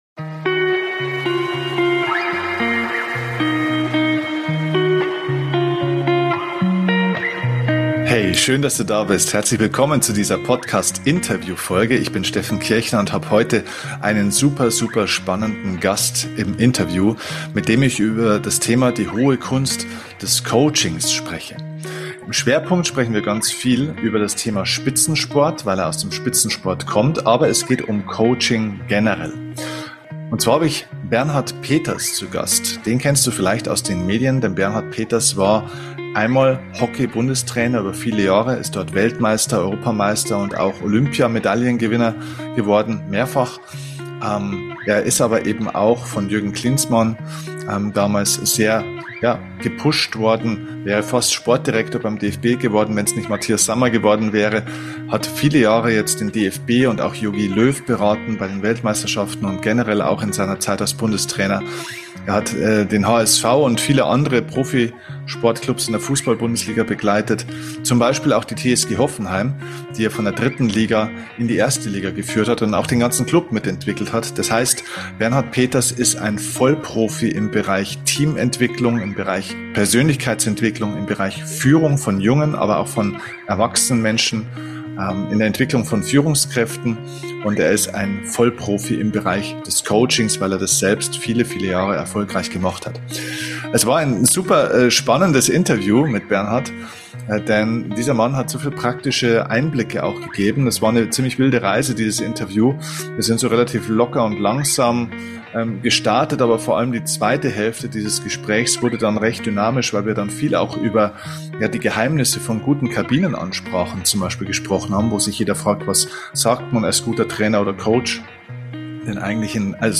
#492 - Die hohe Kunst des Coachings – Interview mit Weltmeister und Ex-Hockey-Bundestrainer Bernhard Peters ~ DIE KUNST ZU LEBEN - Dein Podcast für Lebensglück, moderne Spiritualität, emotionale Freiheit und berufliche Erfüllung Podcast